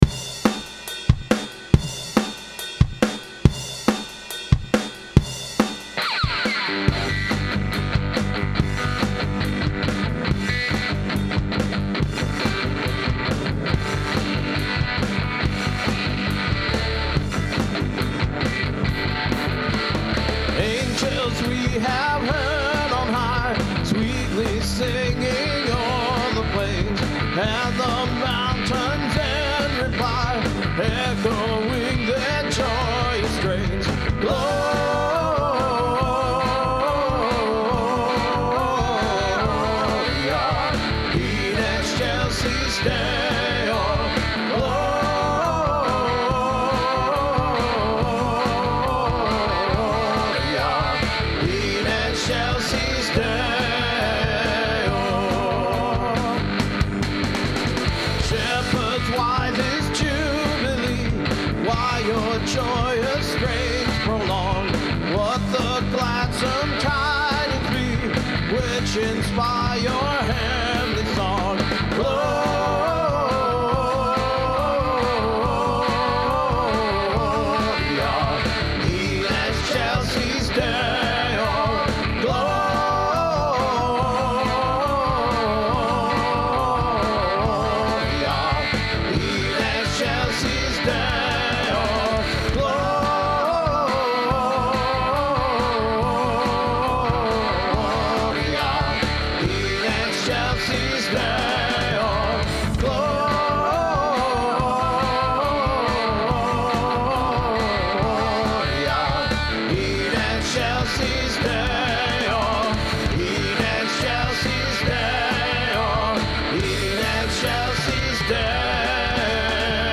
Well… in keeping with our much more straight-ahead rock style, I came up with an 80’s punk version of Angels We Have Heard On High.
The amp was cranked, in the high input, high output, with the booster engaged!
It has a much bigger sound than its 6 Watts! It was actually pretty loud in my studio!